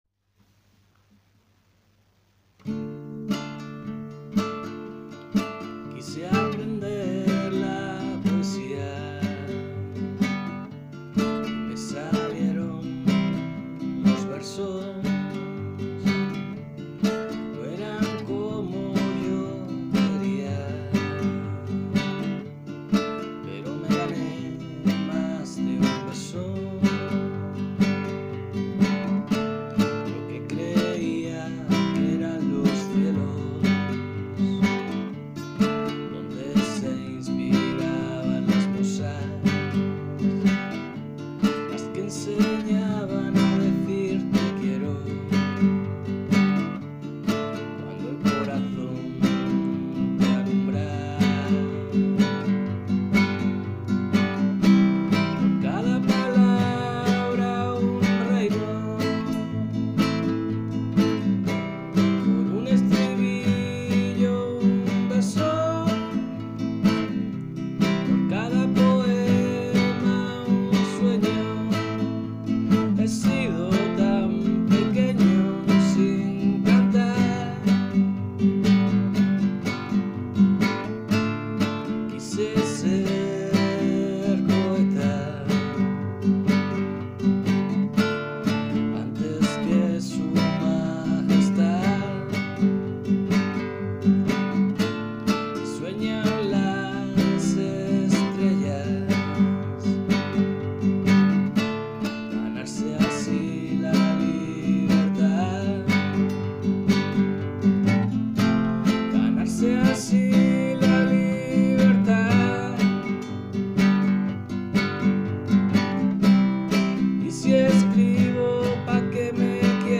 Tableao